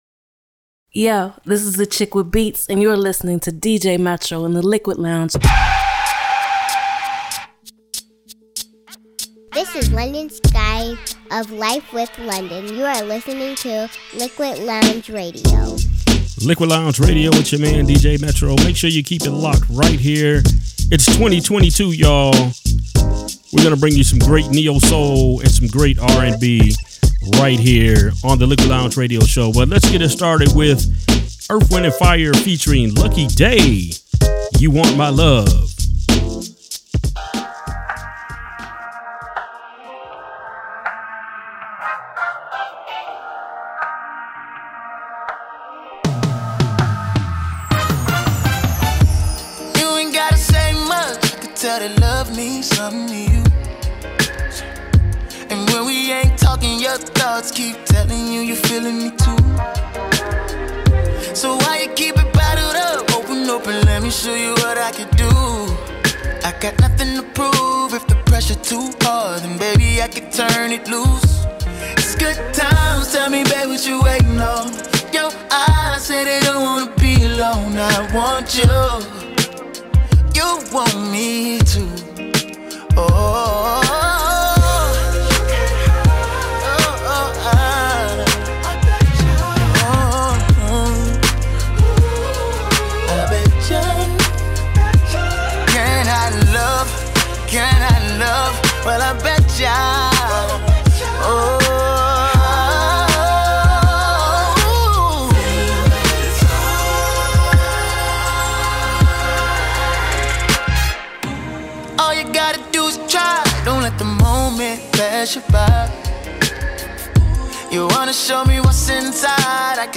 soulful beats within the RnB and Neo-Soul genre